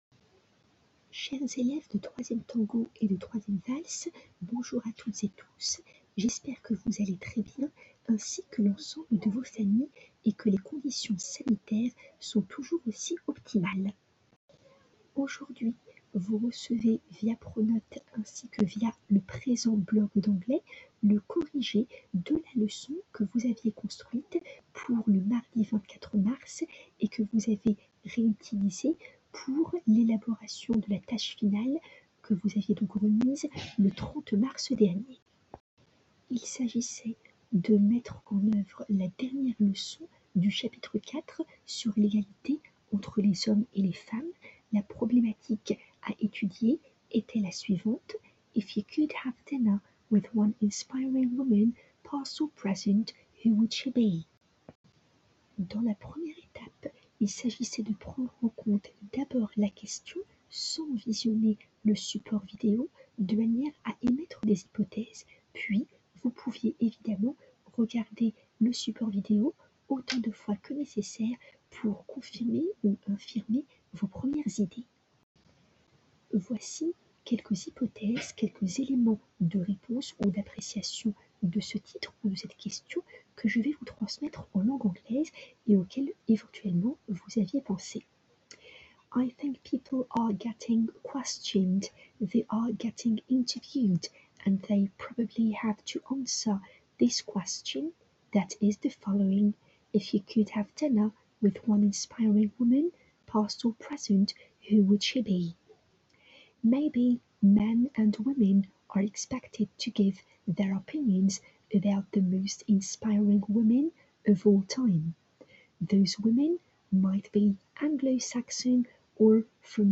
Je vous souhaite une très bonne écoute des pistes audio ci-dessous mettant en avant les explications orales du professeur relativement à la dernière leçon du chapitre 4 et à laquelle vous aviez à réfléchir, en classe inversée, juste avant la tâche finale du 30 mars 2020.
Audio 1 du professeur, d'une durée de 02:24: